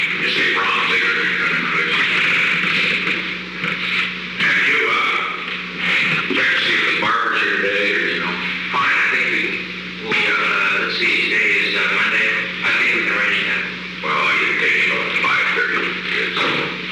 Secret White House Tapes
Conversation No. 917-37
Location: Oval Office
The President met with an unknown man.